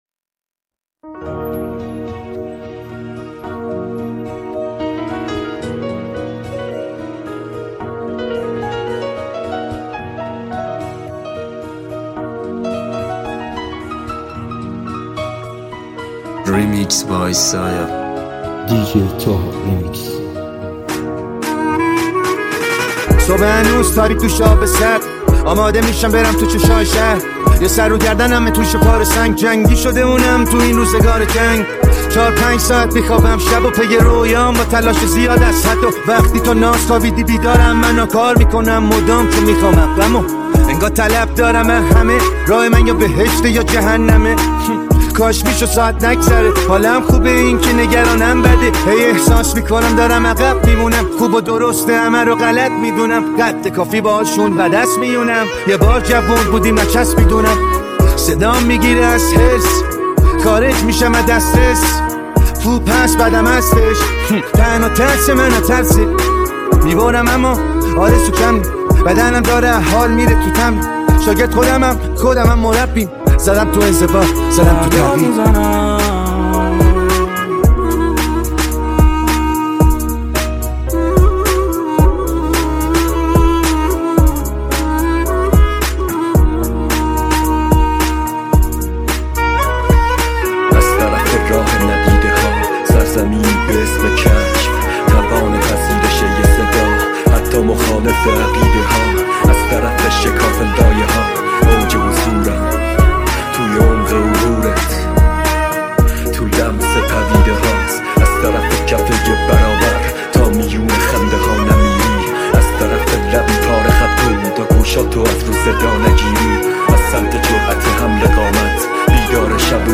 Exclusive Remix
ریمیکس رپ